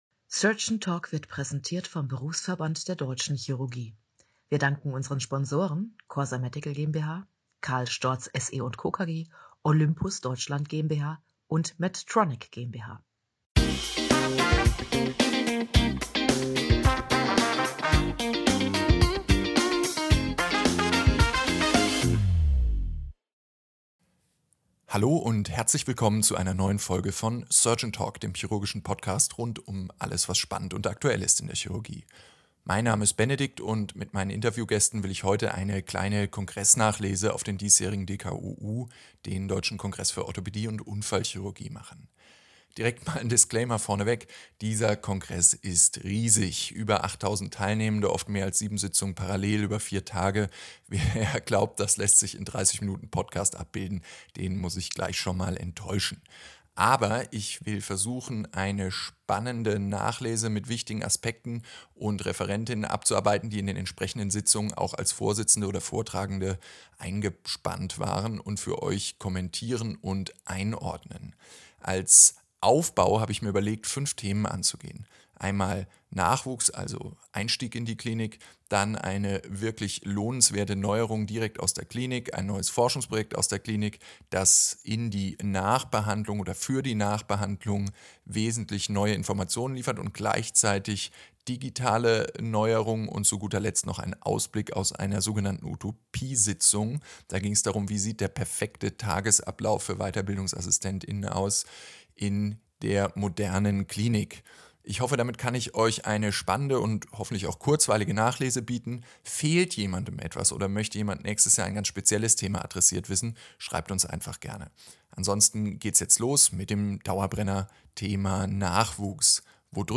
Ton: naja - Inhalt: oh, ha! Kongressnachlese mit Highlights von Nachwuchs über Versorgung und "all things digital", von Reinrednern bis ungeplanten Überraschungsgästen ist alles dabei.